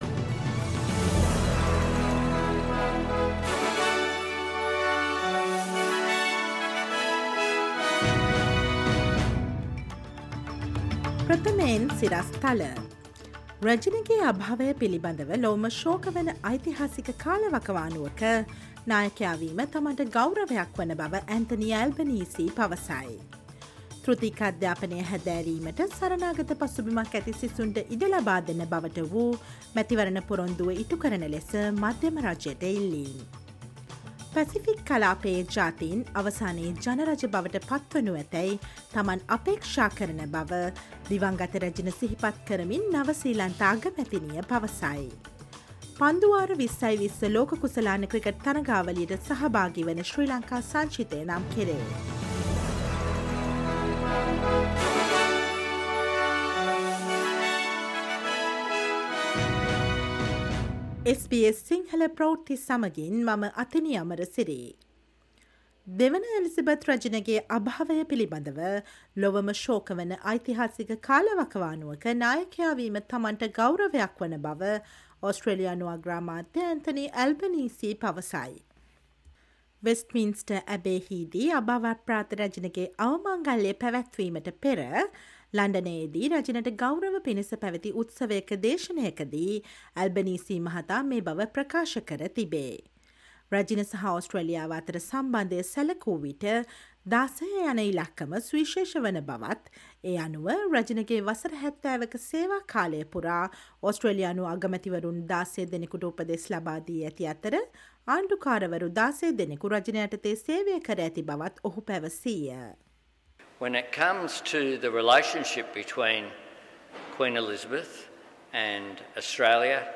Listen to the SBS Sinhala Radio news bulletin on Monday 19 September 2022